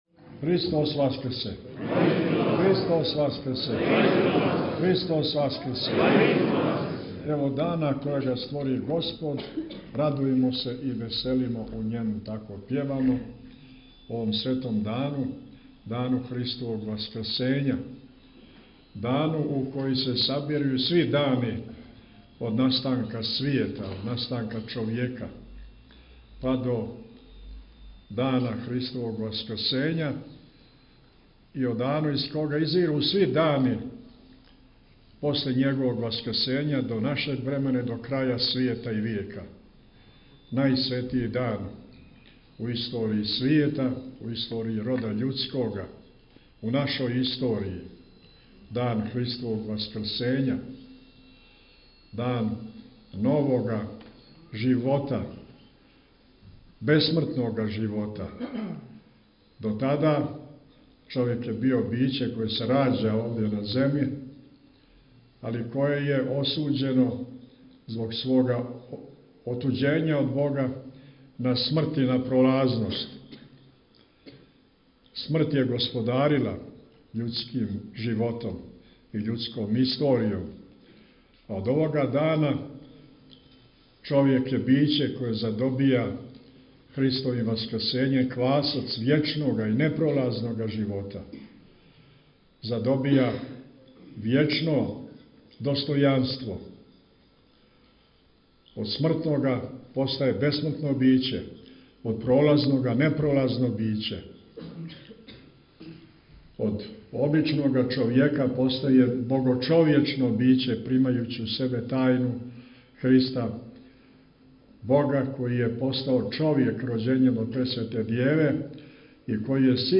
Свету архијерејску литургију у Цркви Свете Петке у Мурини служили су Његово високопреосвештенство Архиепископ цетињски Митрополит црногорско-приморски г. Амфилохије и Његово преосвештенство Епископ будимљанско-никшићки г. Јоаникије са свештенством уз молитвено учешће вјерног народа.